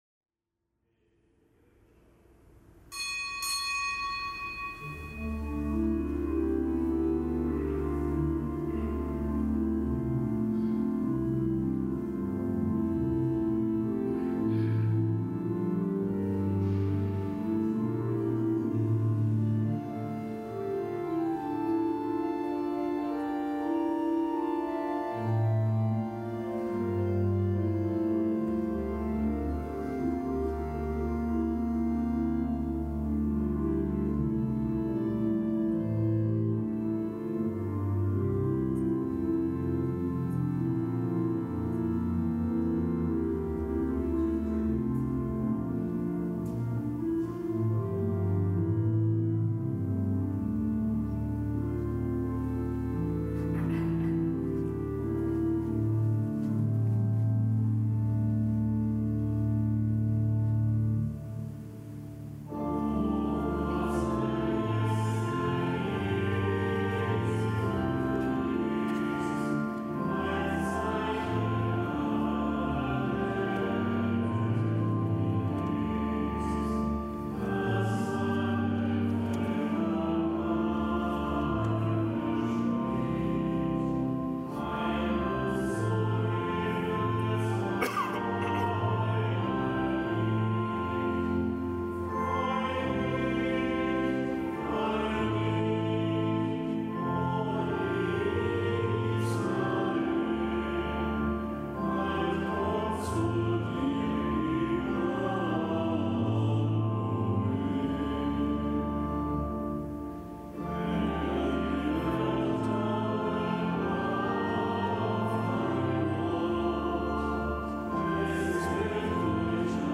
Kapitelsmesse aus dem Kölner Dom am Freitag der dritten Adventswoche.